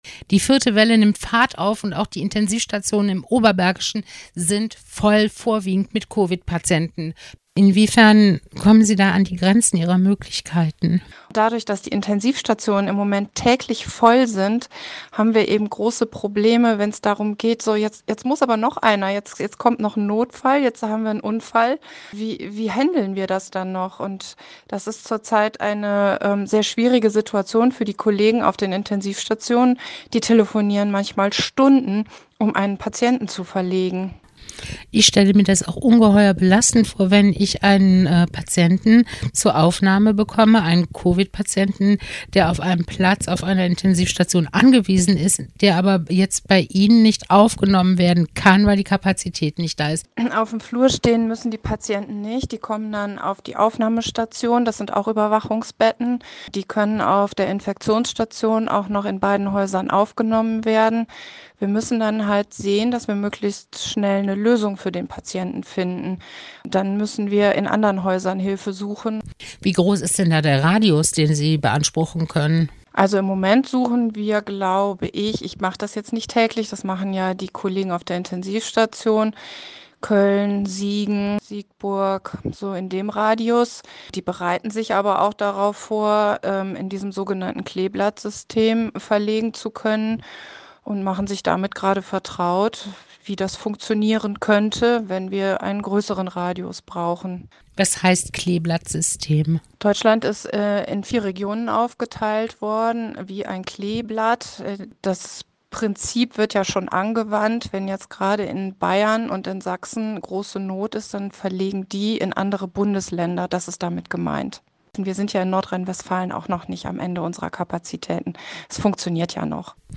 Interviews im Herbst / Winter 2021 - Welle 4